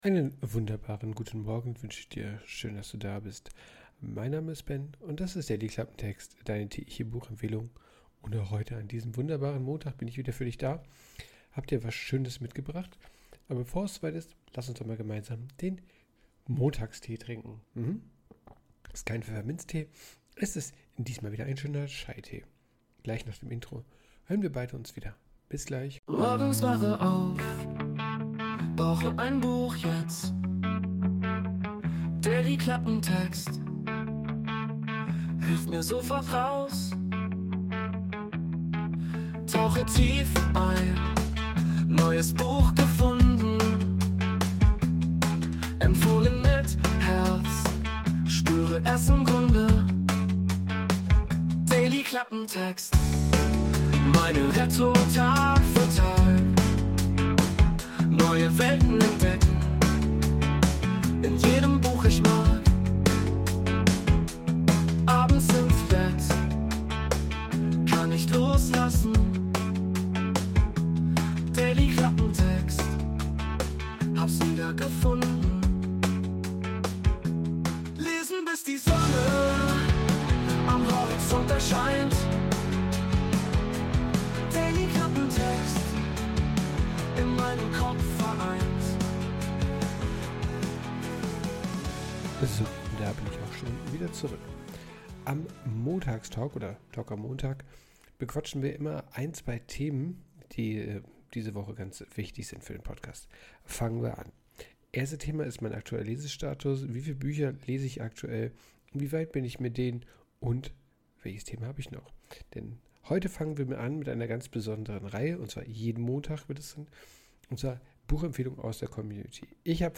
Vielen Dank für die Sprachnachricht!